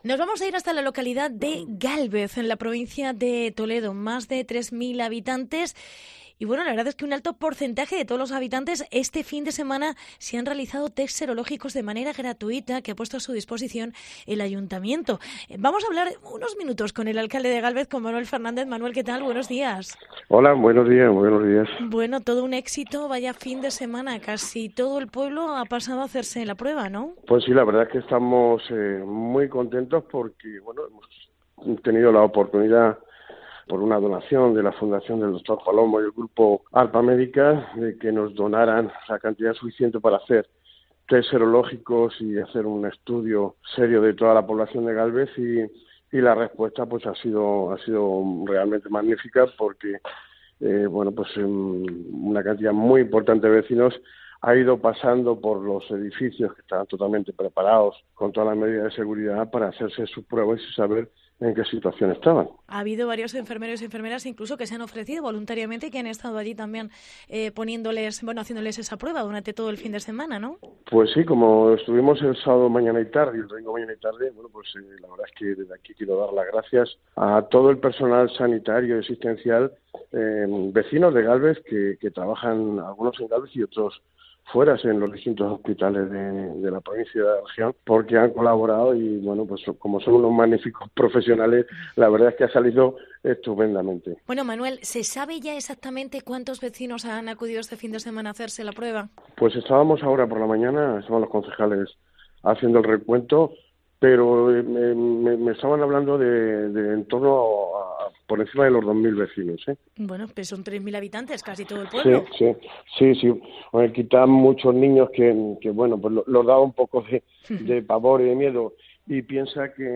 Entrevista Manuel Fernández. Alcalde de Gálvez